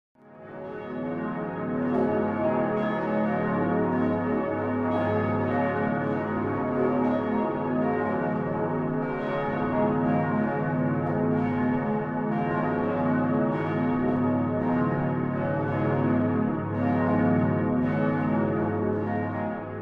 Avec le logiciel Audacity  créer une petite séquence musicale à partir des sons des différentes cloches de la cathédrale de Strasbourg
grande-sonnerie33.mp3